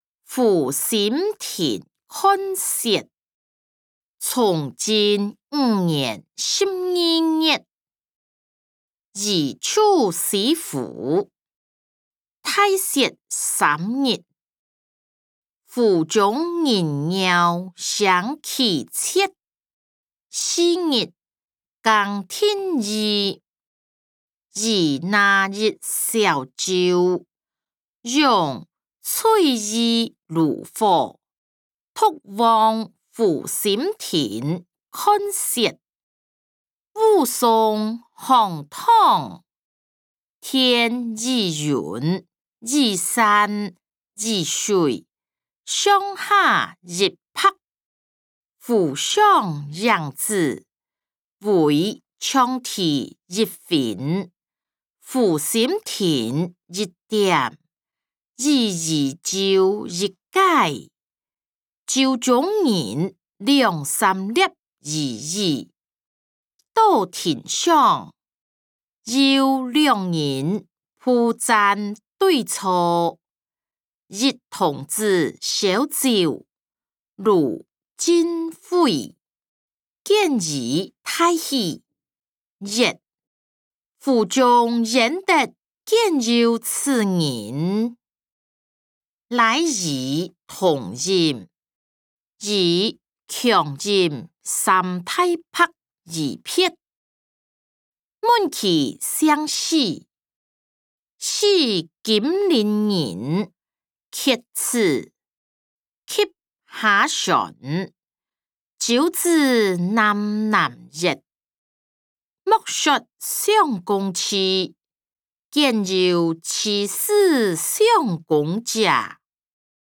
歷代散文-湖心亭看雪音檔(大埔腔)